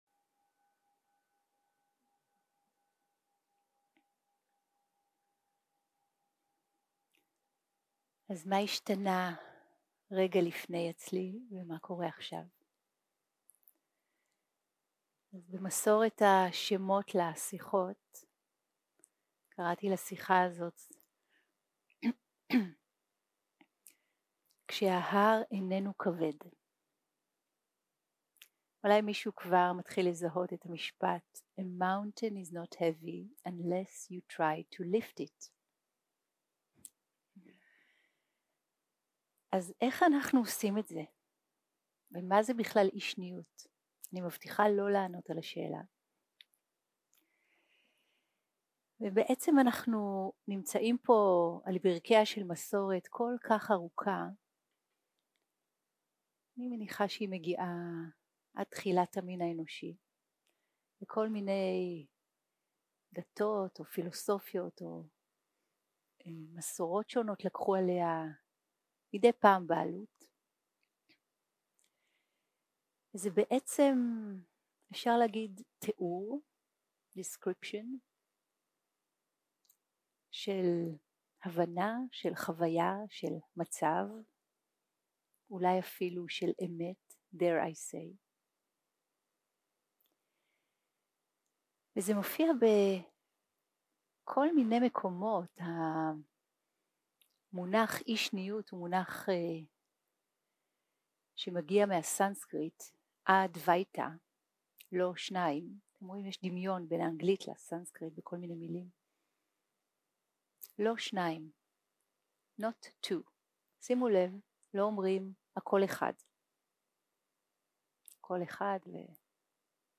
יום 2 - הקלטה 4 - ערב - שיחת דהרמה - כשההר איננו כבד
Dharma type: Dharma Talks שפת ההקלטה